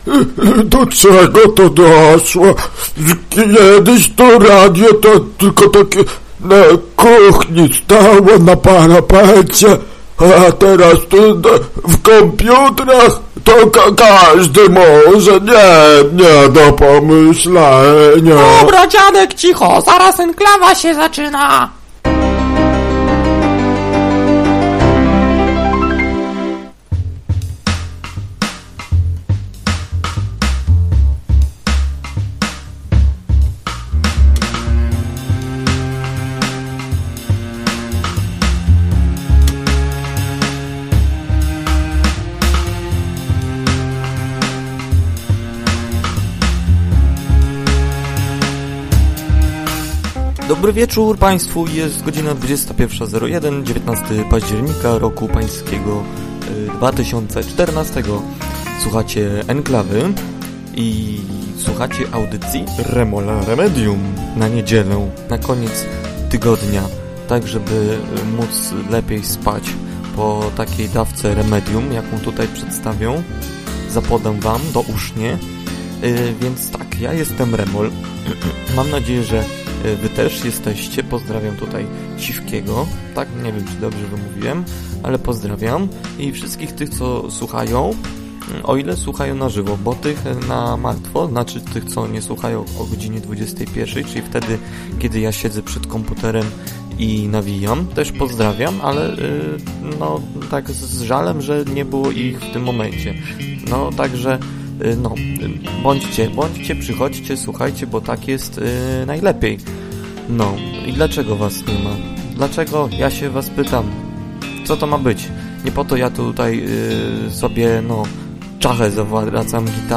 Audycja z - jak się okazało po fakcie - źle ustawionymi suwaczkami, przez co muzyka nieco zagłusza głos.